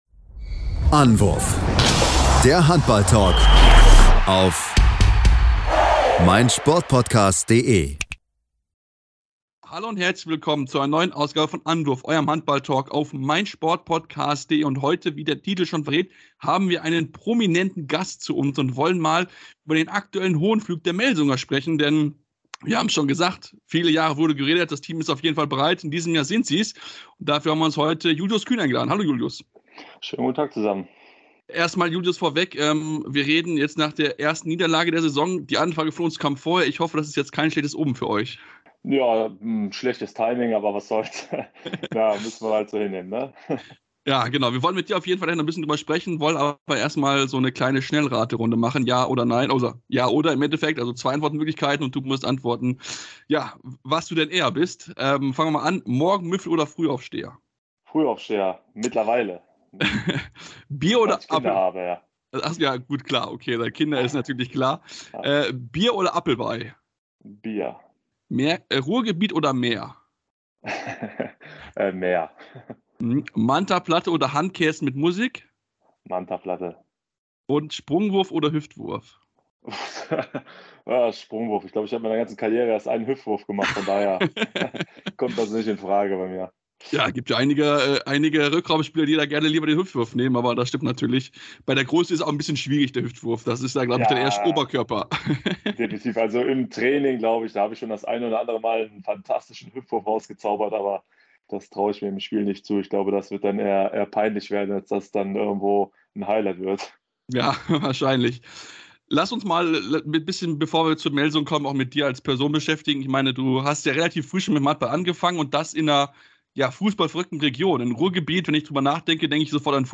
Interview mit Julius Kühn ~ Handball Podcast
interview-mit-julius-kuehn.mp3